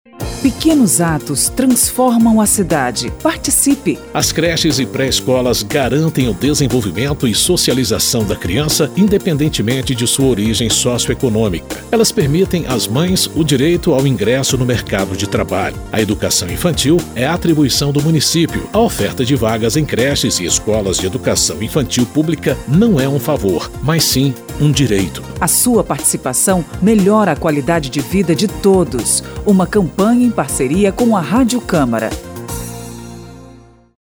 São 7 spots de 30 segundos sobre saúde, transporte, educação e segurança, destacando o papel de cada um – prefeito, vereadores e cidadãos – na melhoria da vida de todos.
spot-pequenos-atos-3.mp3